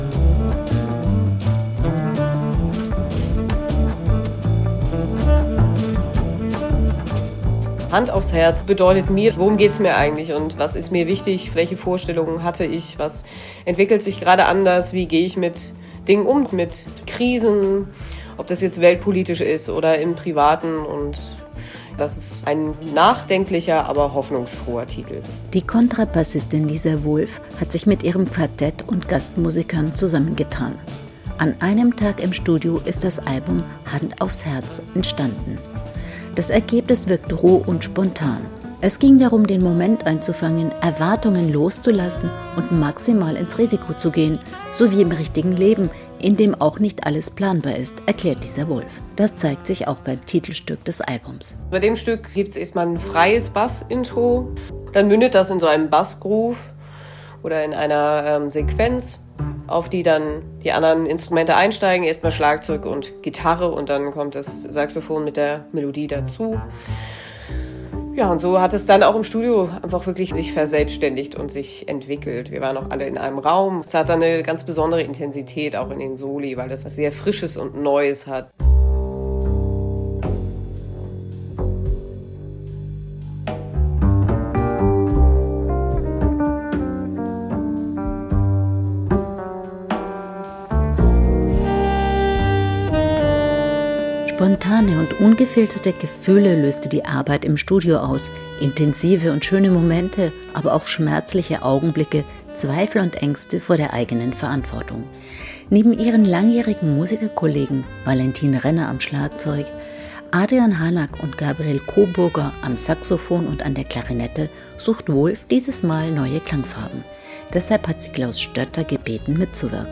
Album-Tipp Jazz